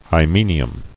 [hy·me·ni·um]